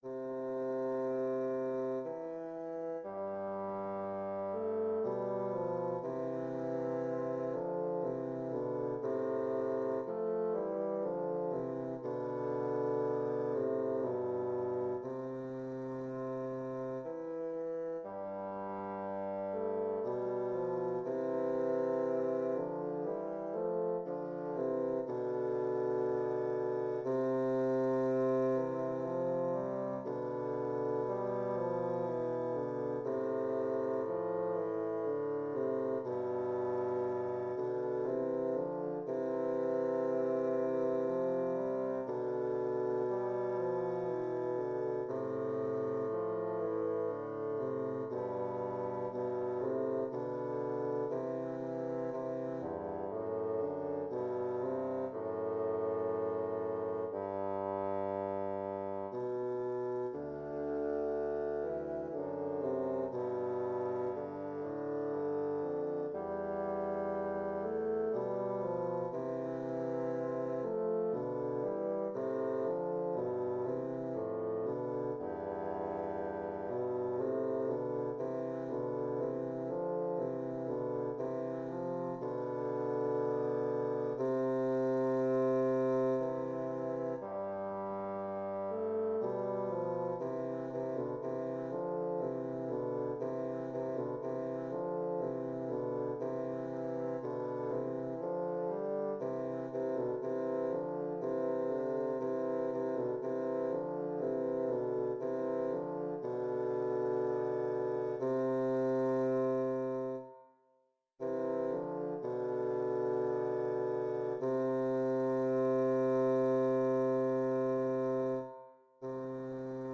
Intermediate bassoon duet
Instrumentation: Bassoon duet
tags: bassoon music